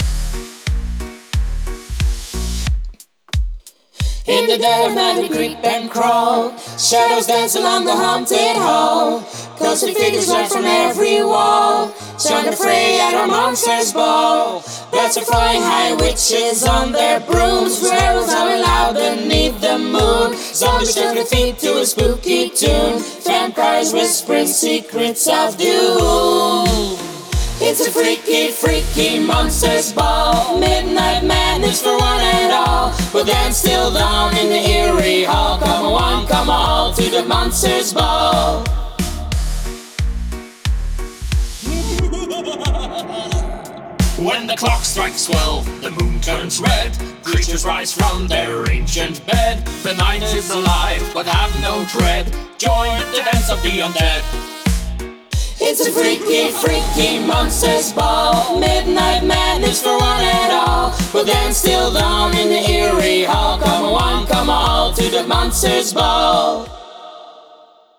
Alle Stemmen